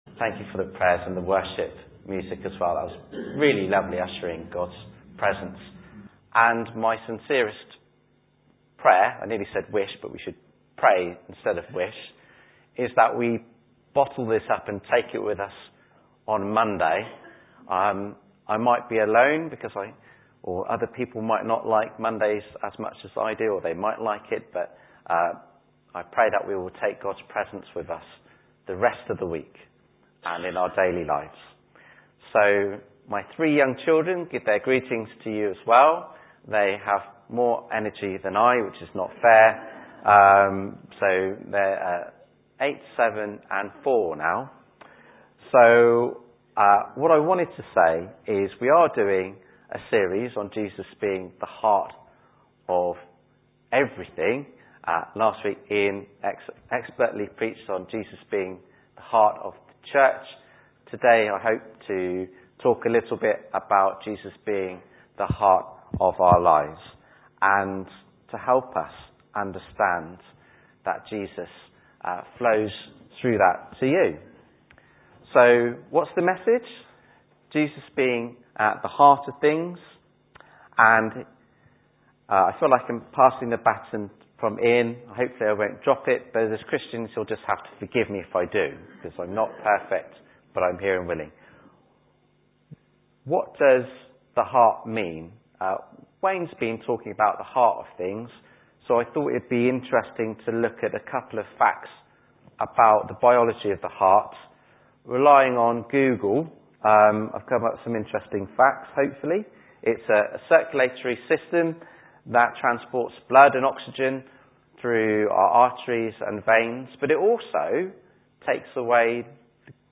Billericay Baptist Church - sermons Podcast - Jesus the heart of your life | Free Listening on Podbean App